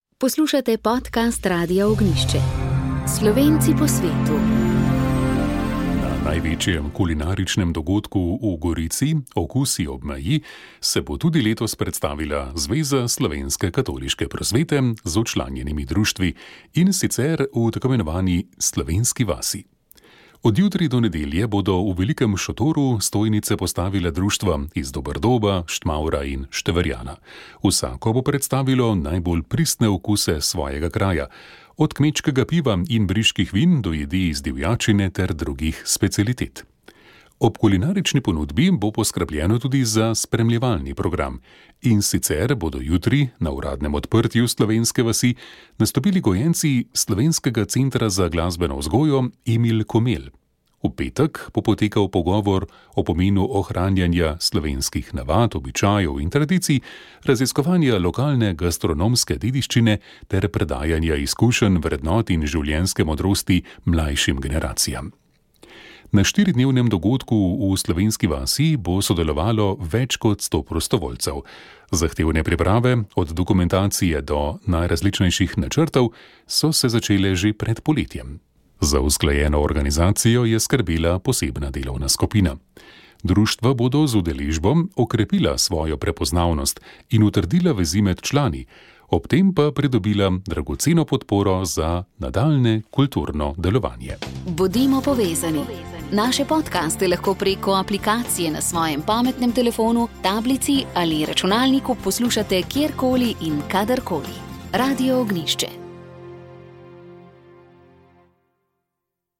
komentar politika družba Robert Golob reforme volitve